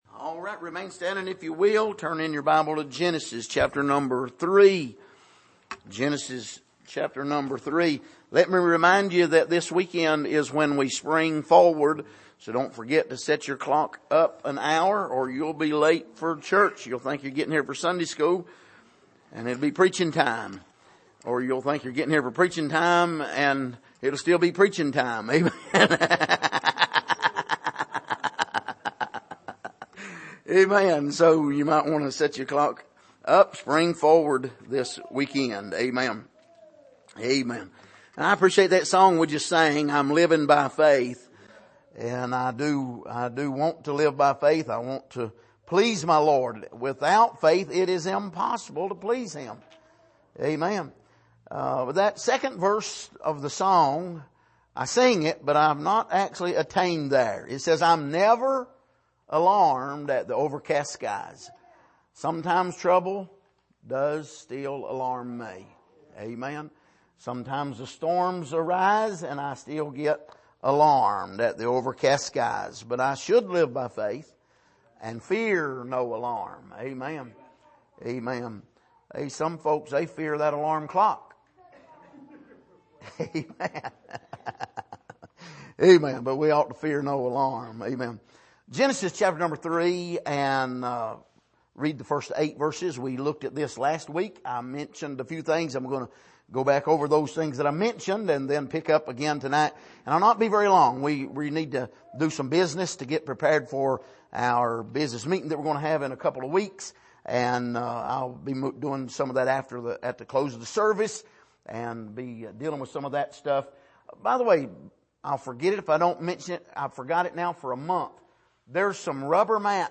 Miscellaneous Passage: Genesis 3:1-8 Service: Midweek